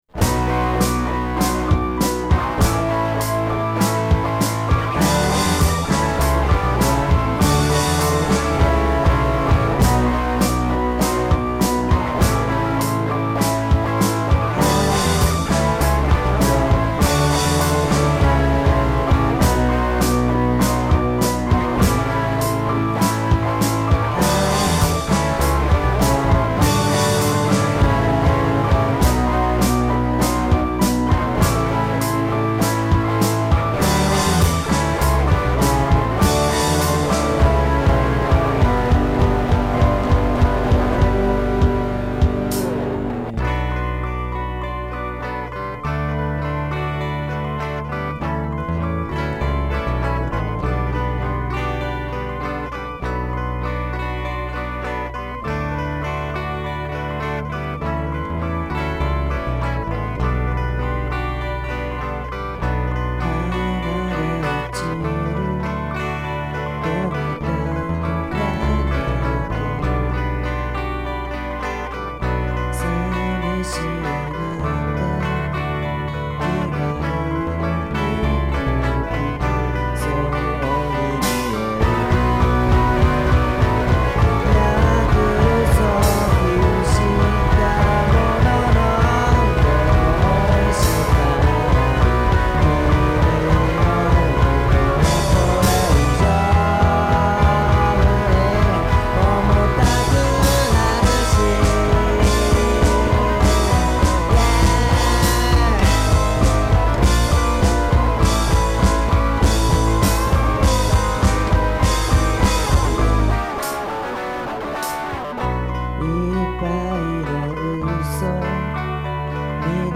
メンバーがみつからないのでバンドをあきらめてひとりで制作したアルバム。
BOSSのふつうのドラムマシンでやってます。